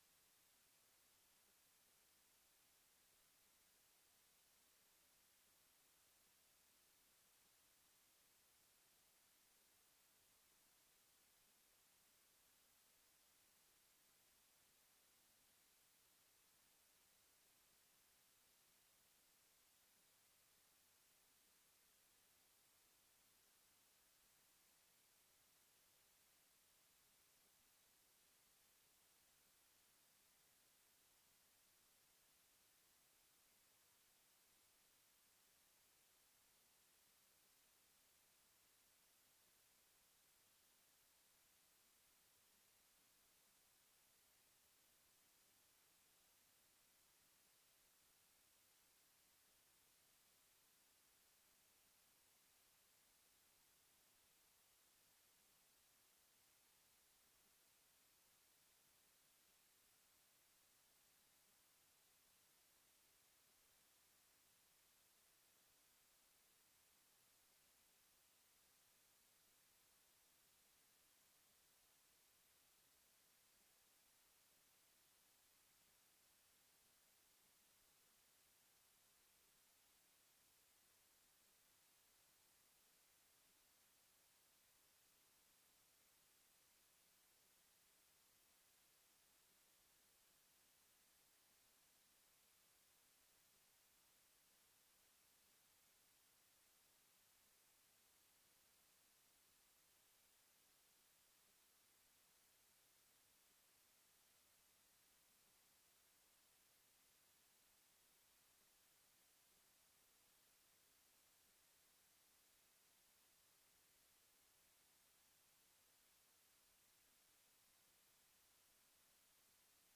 This sermon unpacks Hosea 4 to contrast a true knowledge of God with empty, outward religion. God calls His people into a covenant “courtroom,” exposing lives marked by habitual sin and religious form without faithfulness, love, or real relationship with Him.